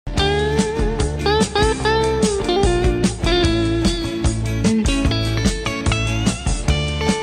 Guitar - Original Audio